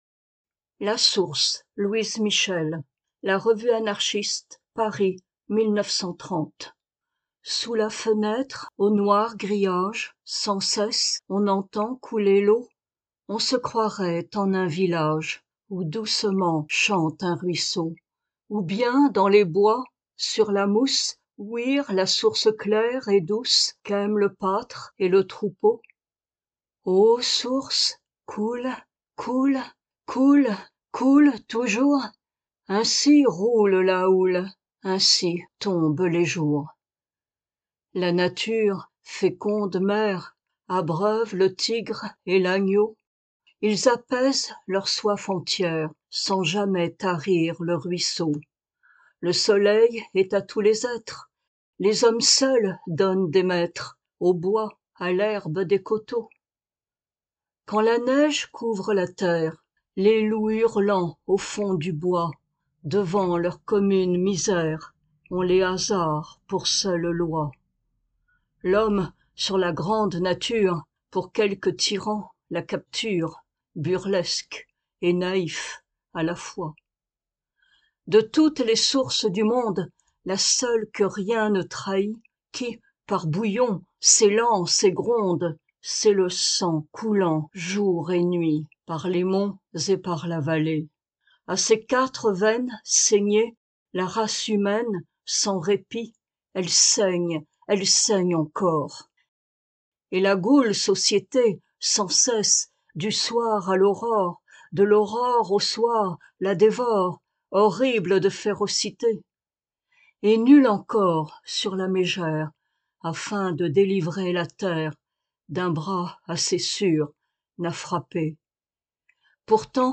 Rubrique:poesies
Résumé du livre audio: La Source, La Nuit de la Mort de Vaillant